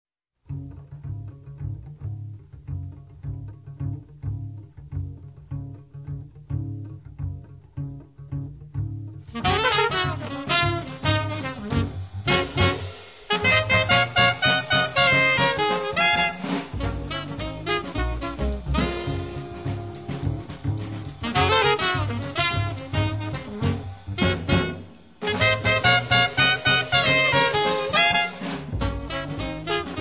• free jazz
• jazz moderno
• registrazione sonora di musica